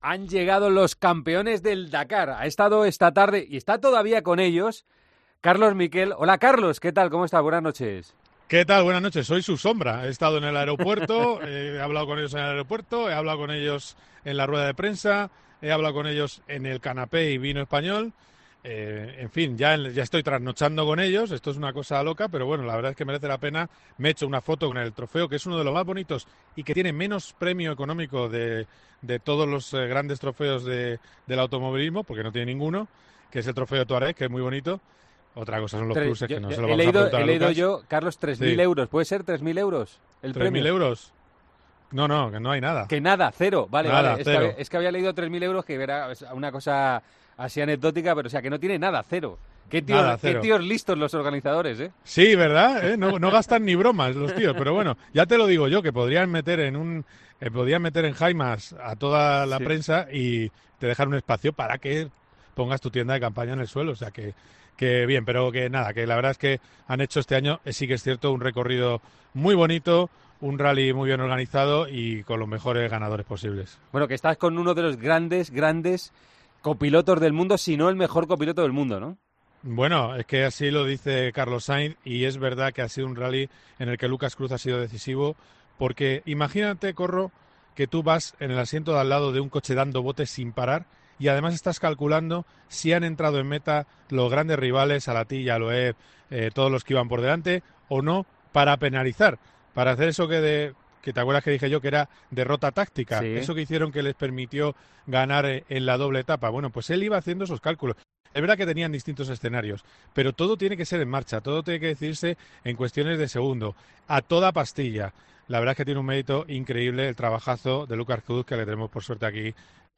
Tras dos largas semanas de aventura Carlos Sainz y Lucas Cruz ya se encuentran en España y el copiloto catalán ha pasado por los micrófonos de Tiempo de Juego para analizar el triunfo español en el Dakar.